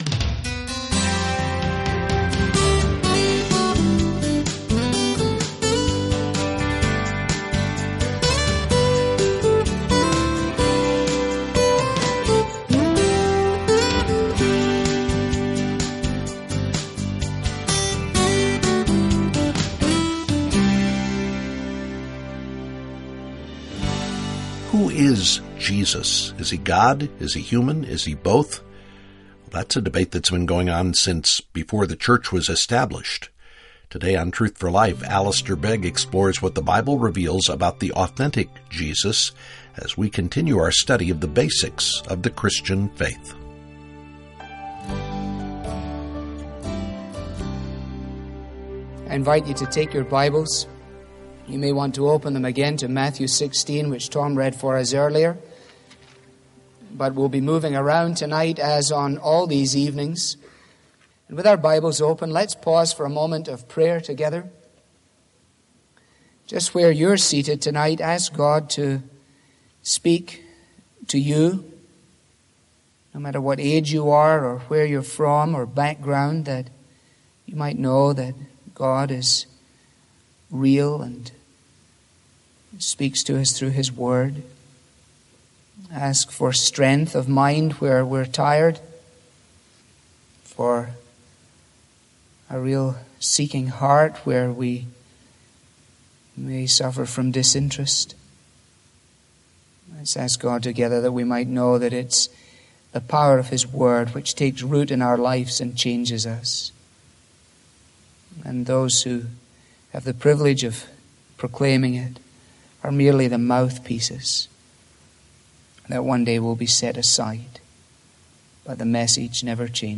This listener-funded program features the clear, relevant Bible teaching